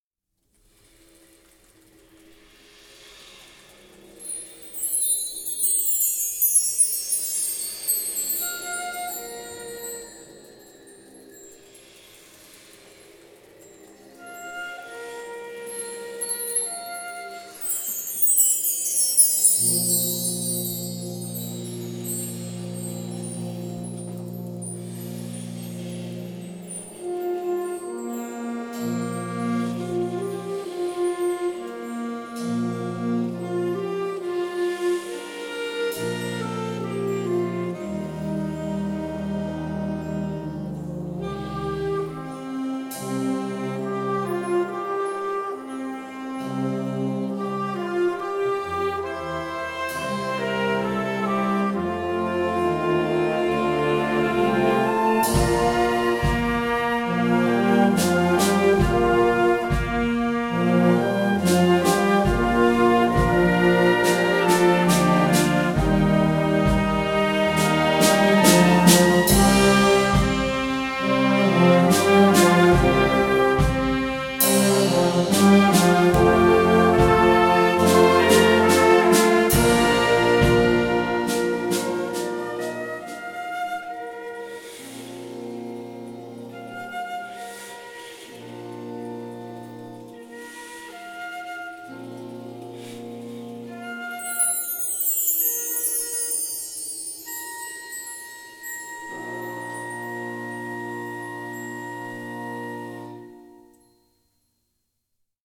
Gattung: Moderner Einzeltitel Jugendblasorchester
Besetzung: Blasorchester